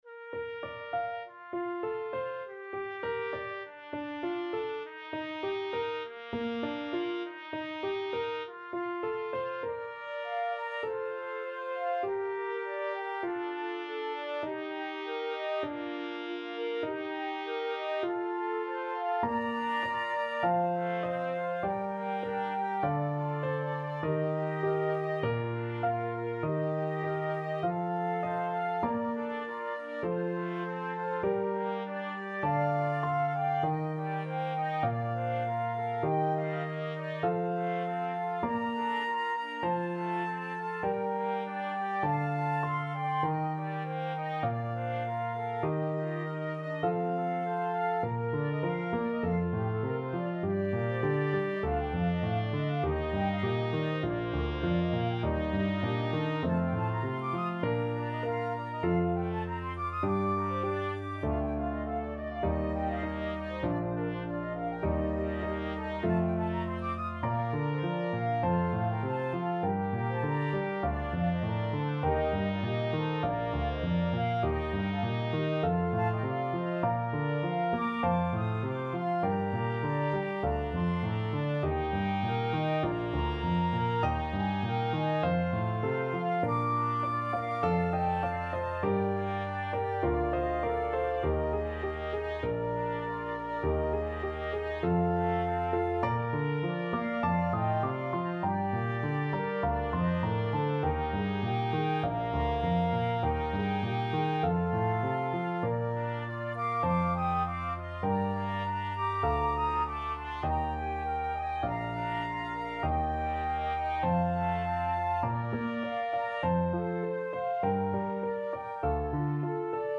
Classical
Flute
Trumpet